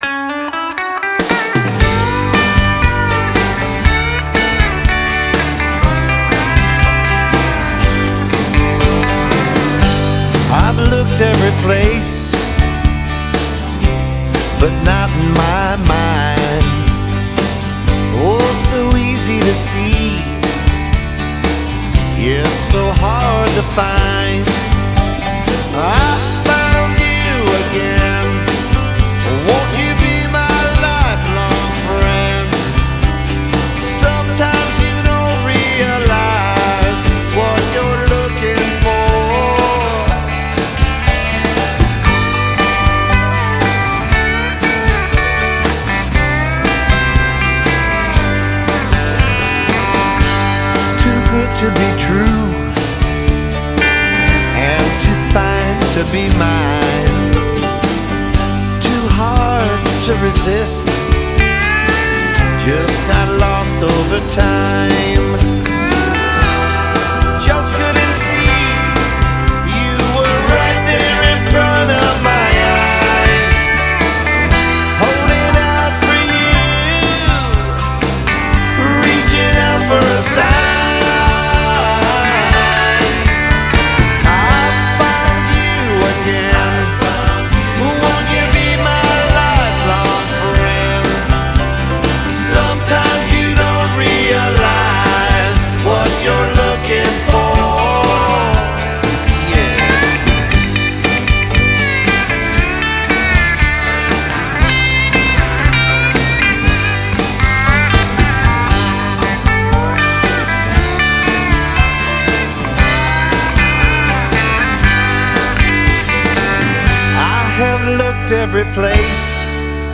country-ish